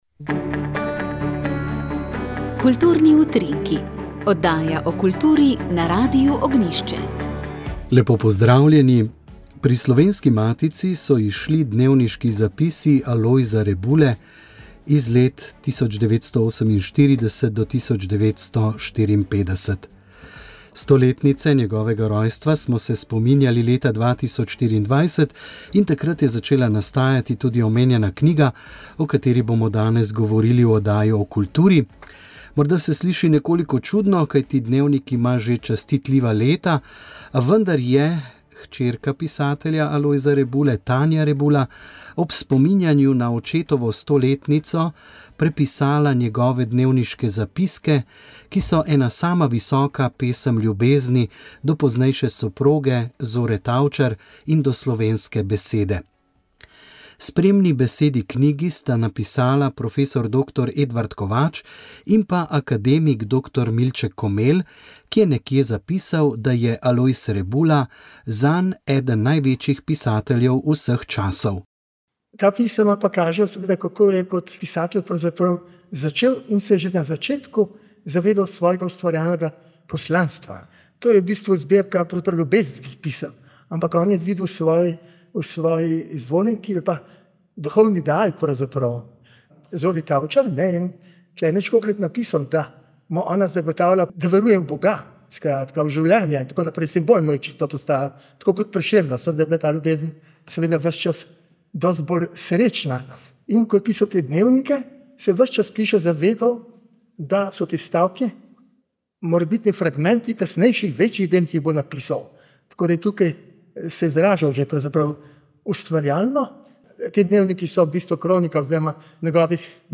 V tokratni nedeljski kmetijski oddaji ste lahko slišali povzetek slavnostnega predavanja